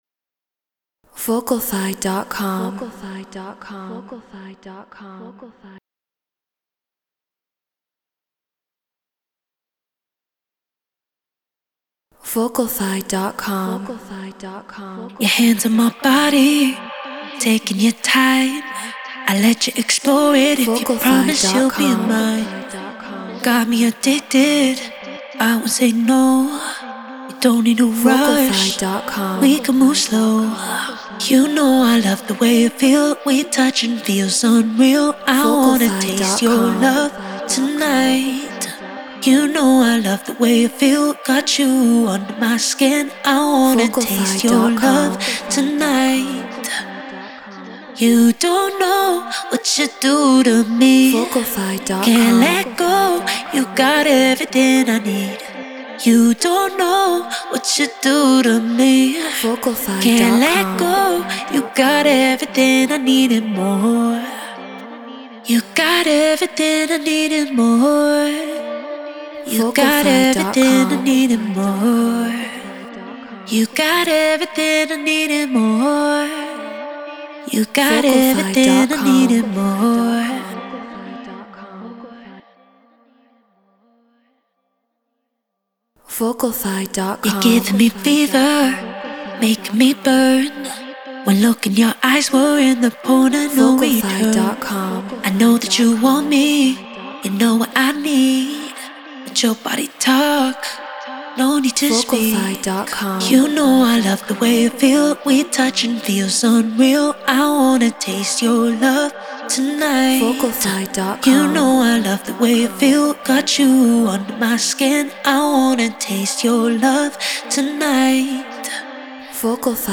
Afro House 120 BPM Gmin